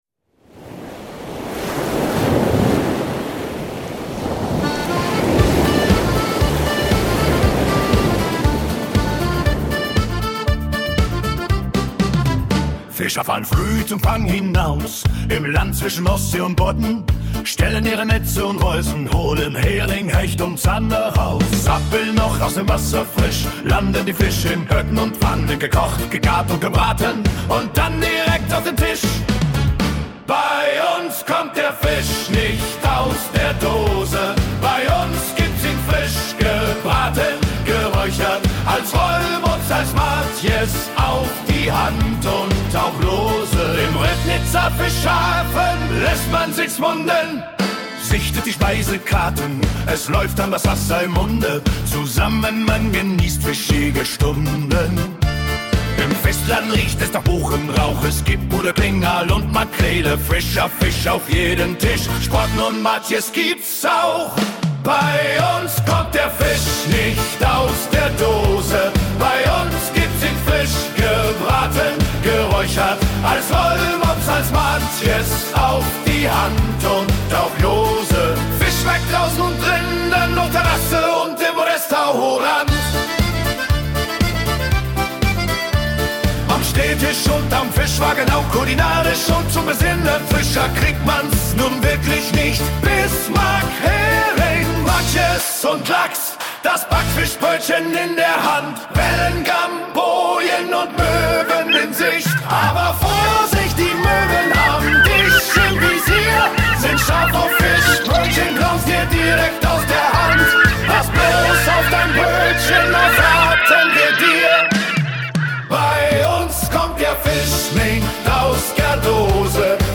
1_ribfish_deutsch_shanty.mp3